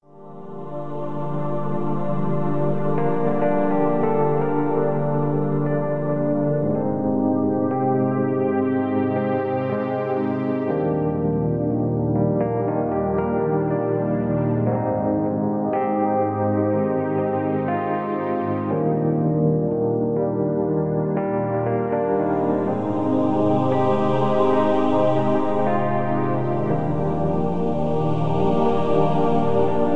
Hier ist die Musik OHNE Sprache.